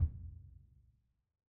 Percussion
BDrumNewhit_v2_rr1_Sum.wav